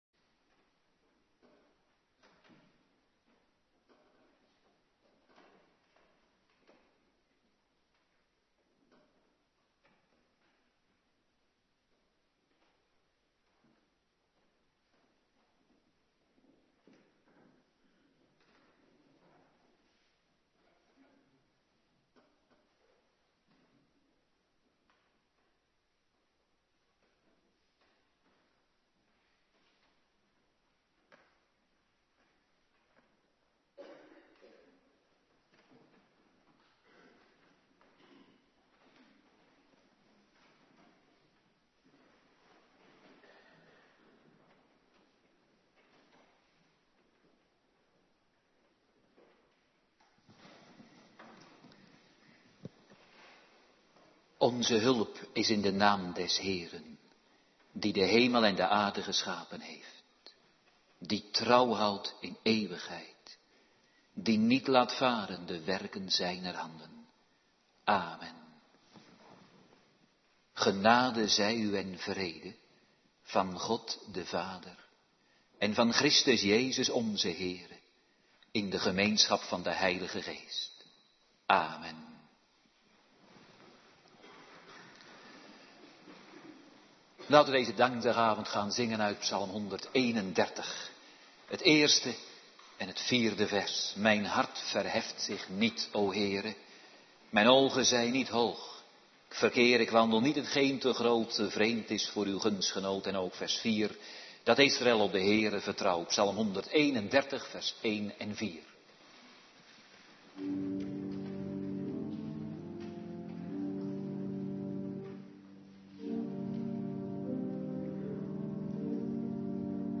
Avonddienst dankdag
19:30 t/m 21:00 Locatie: Hervormde Gemeente Waarder Agenda